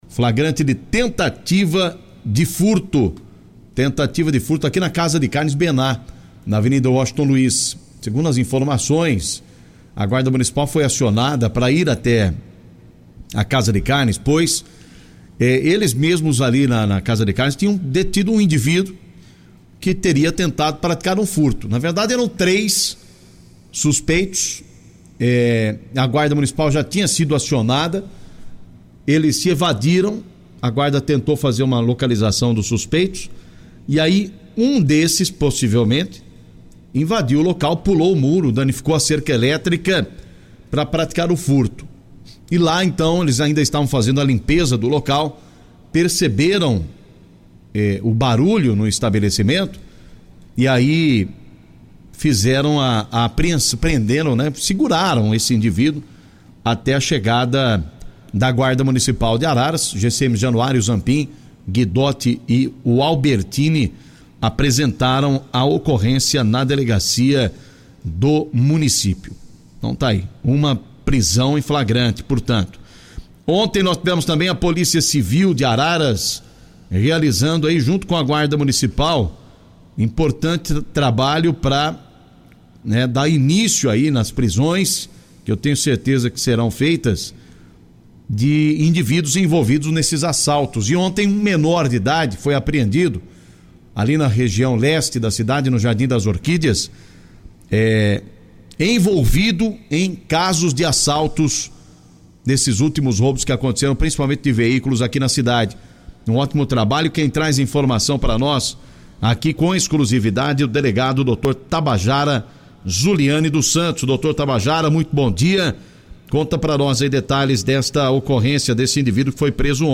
ouça as principais notícias de Araras e região na voz do repórter policial